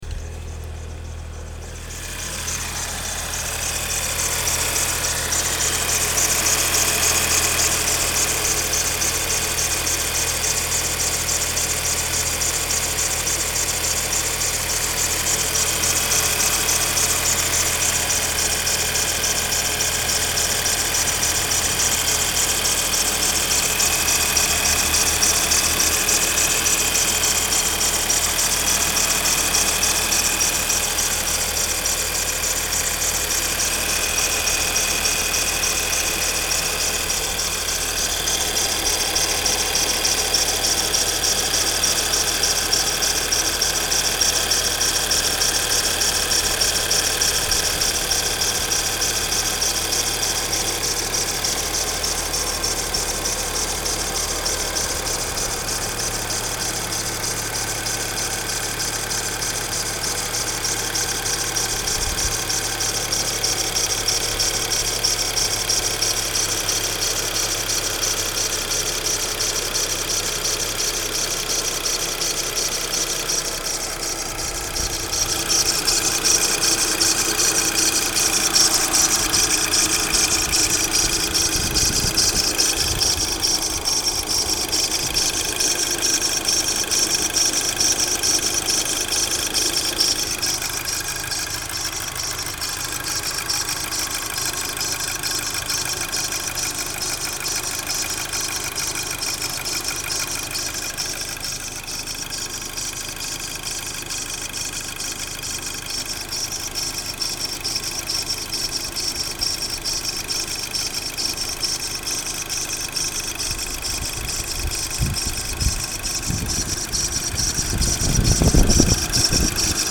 To me, it sounded like a cam belt issue.. the squeaking/rotating of the cam belt.. i guess i will know tomorrow.
The timing belt tensioners go bad and squeak until they warm up.
gtinoise.mp3